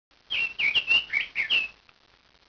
bird2.wav